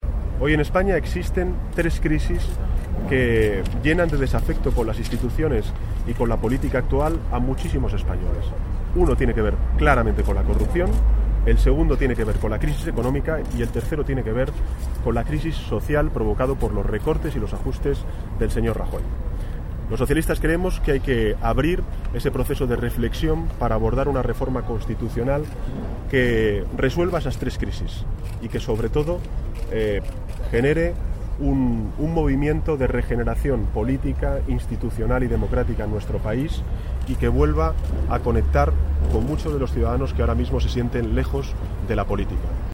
Declaraciones de Pedro Sánchez tras registrar en el Congreso la petición para crear una subcomisión que estudie la reforma de la Constitución 4/12/2014